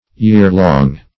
Search Result for " year-long" : The Collaborative International Dictionary of English v.0.48: year-long \year"-long\ yearlong \year"long\, adj. 1. lasting through a year. attending year-long courses Syn: yearlong.